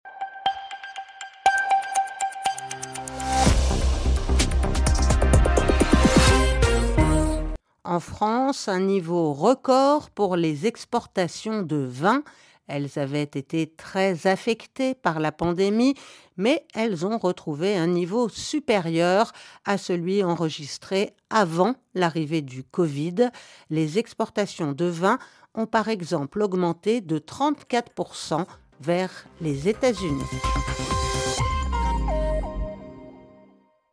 Extrait du Journal en français facile du 15/02/2022 (RFI)